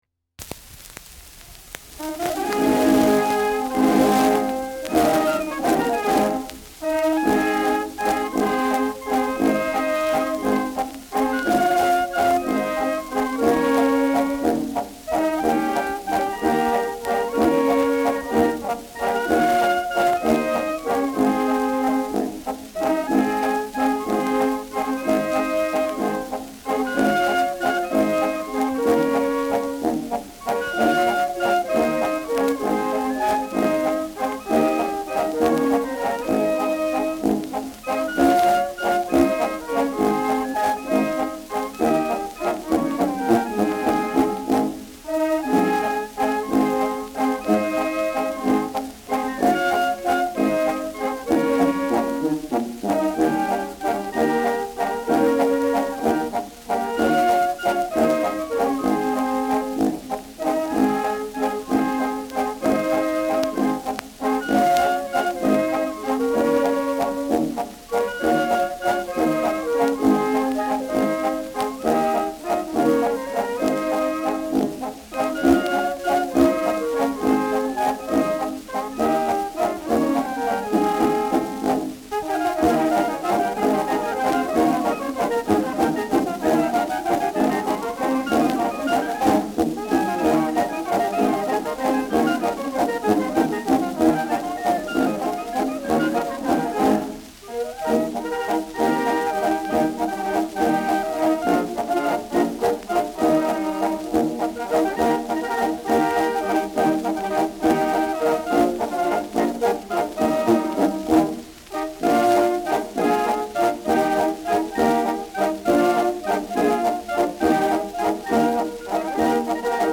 Schellackplatte
präsentes Knistern : leichtes Rauschen : leiert
Dachauer Bauernkapelle (Interpretation)